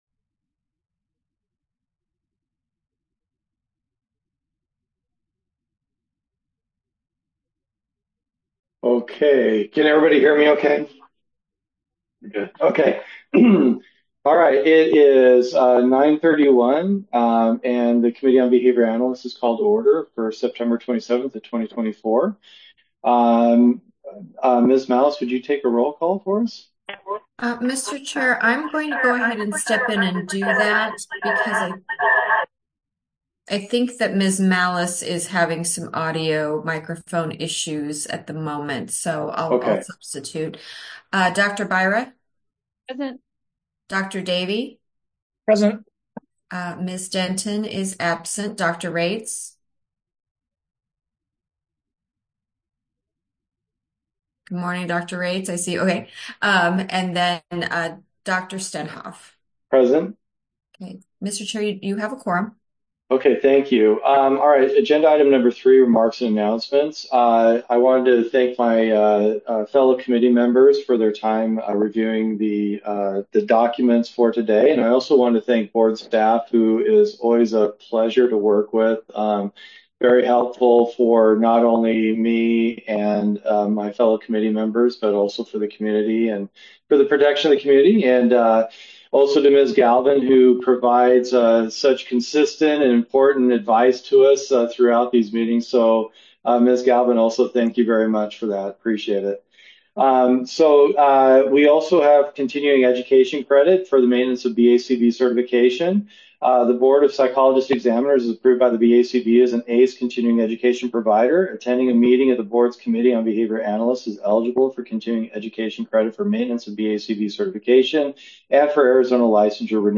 Members will participate via Zoom.